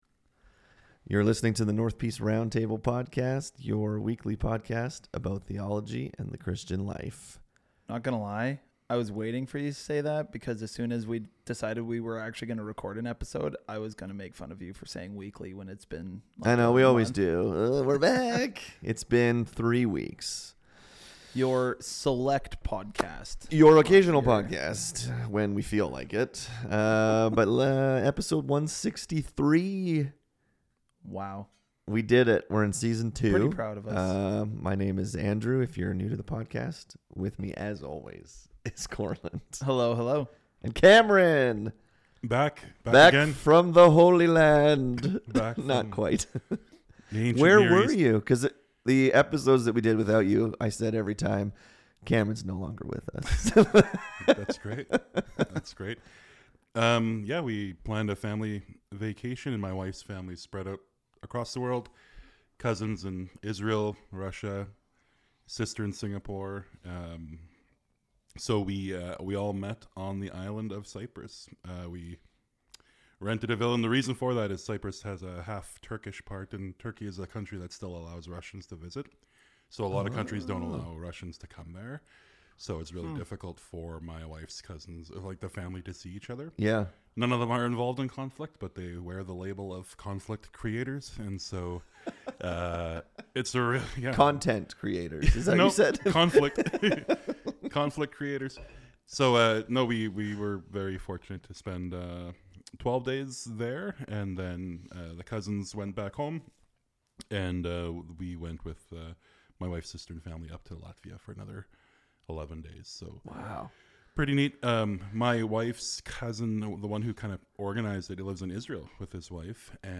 In this episode the guys talk about a biblical view of dating. What is the purpose of dating? Should Christians date non-Christians?